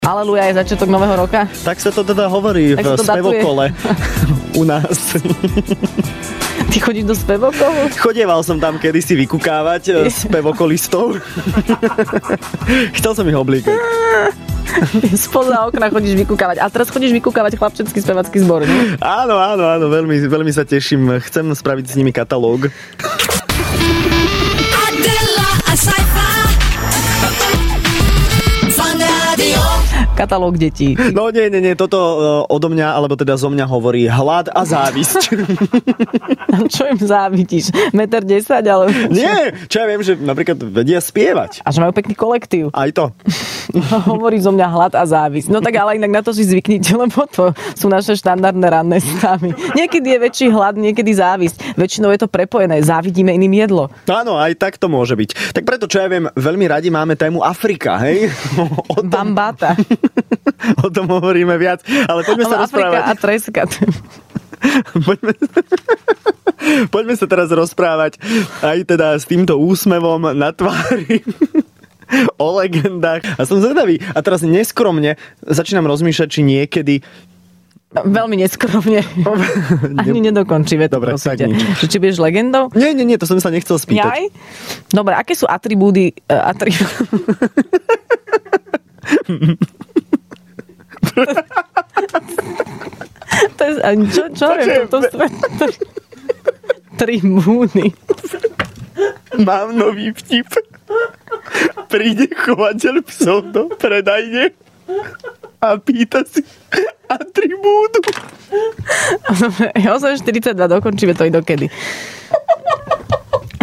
Záchvaty smiechu
Adela so Sajfom mali v Rannej šou poriadne veselo. Kvôli smiechu ani nedokončili, čo chceli povedať...